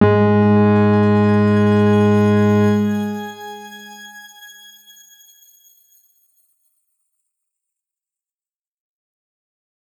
X_Grain-G#2-mf.wav